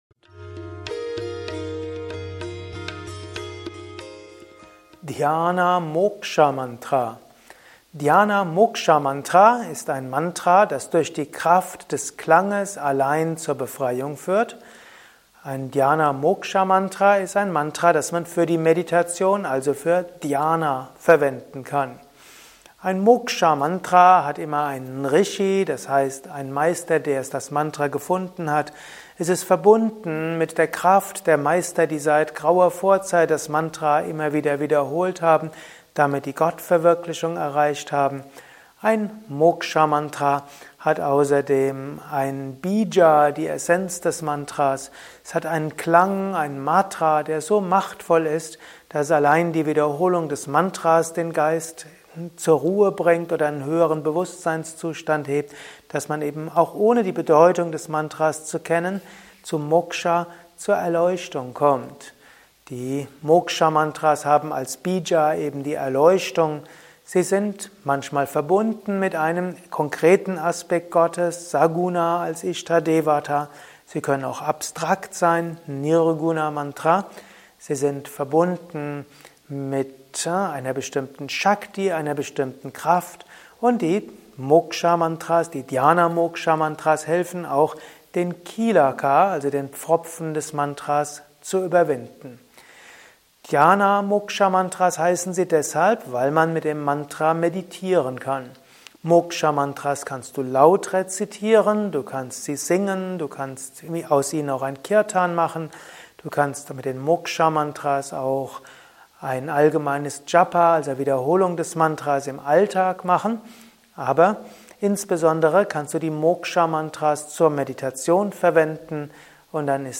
Mantra-Meditation-Podcast ist die Tonspur eines Mantra Videos.